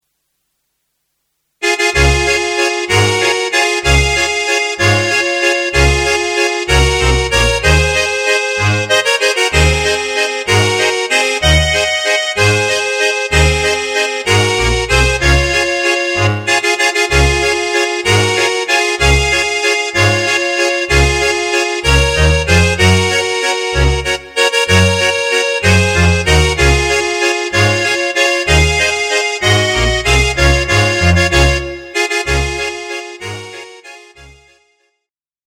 Db-Dur